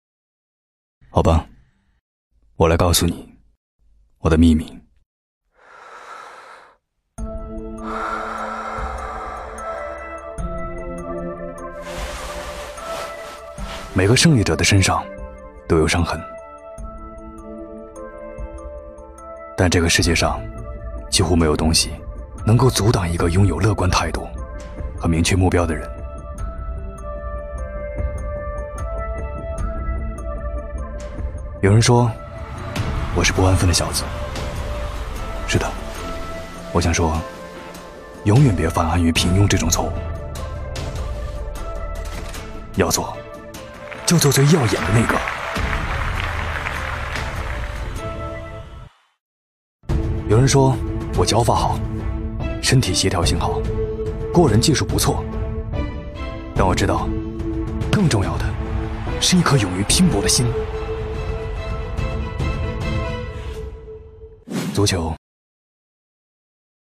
男32高端广告-纵声配音网